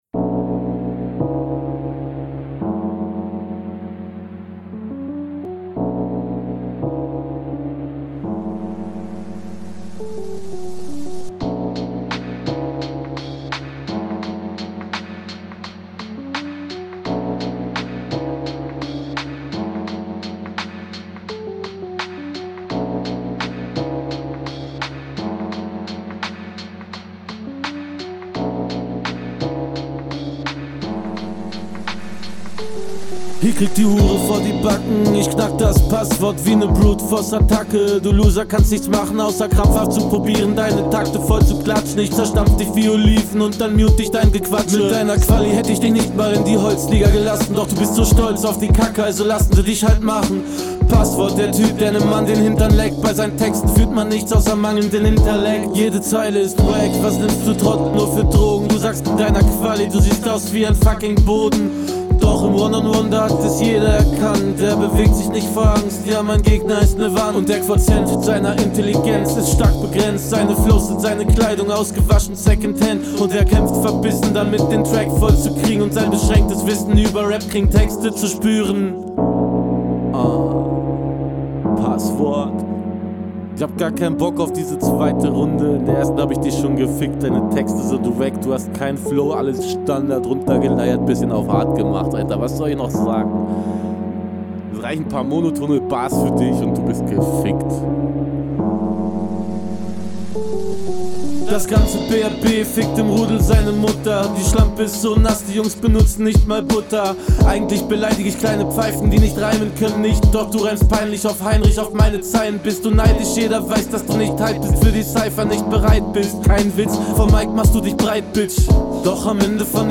cooler beat, aber bisschen was vom intro hättest du wegschneiden können stimmlich besser als in …